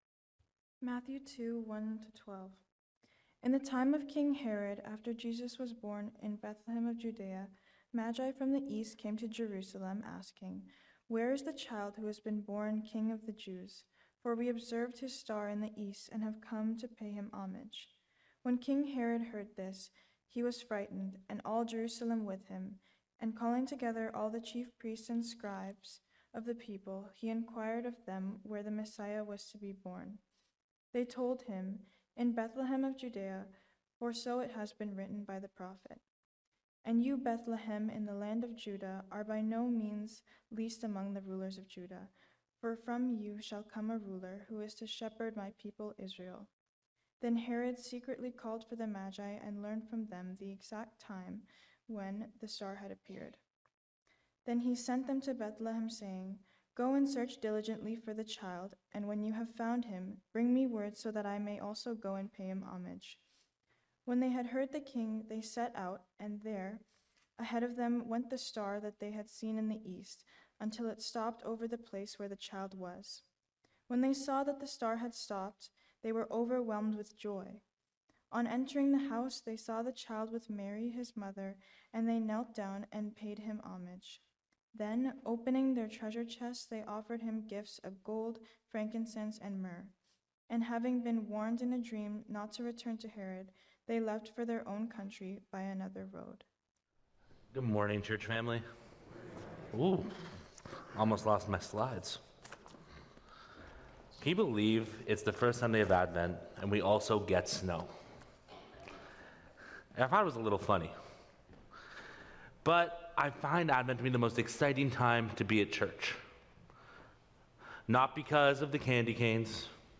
Sermons | Weston Park Baptist Church